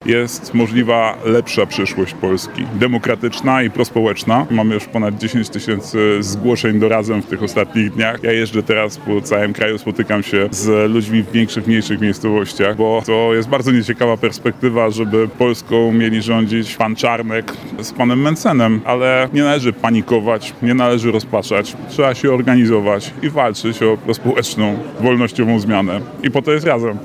Do partii Razem zgłosiło się w ostatnich dniach 10 tysięcy nowych osób – przekazał w Lublinie współprzewodniczący partii Razem, Adrian Zandberg, który spotkał się ze studentami Wydziału Politologii i Dziennikarstwa UMCS.